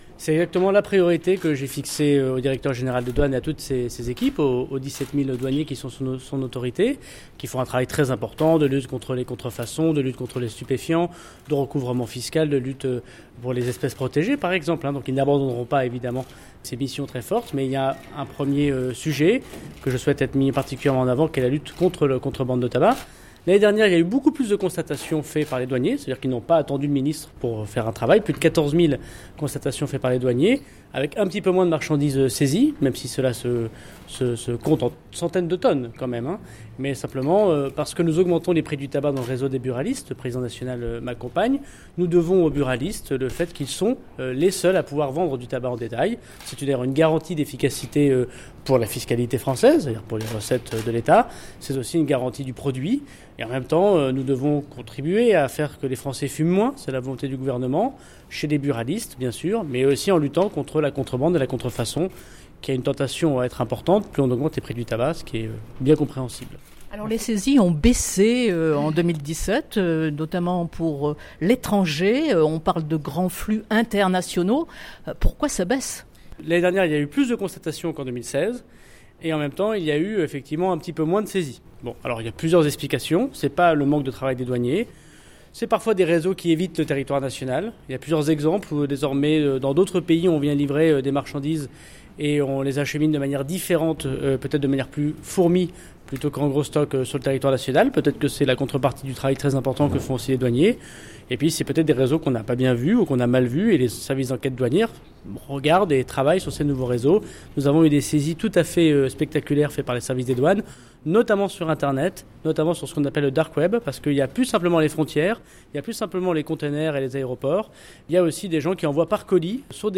son_copie_petit-255.jpgEntretien avec Gérald Darmanin qui, à l’issue de sa deuxième journée à Marseille a réaffirmé que la lutte contre la contrebande de tabac est sa priorité.